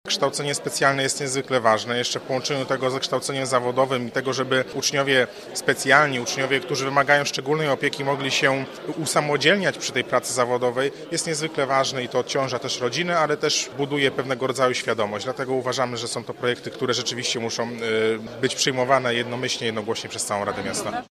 – Już w połowie ubiegłego roku, skierowaliśmy do władz miasta interpelacje w tej sprawie – mówi przewodniczący klubu radnych PiS Paweł Ludniewski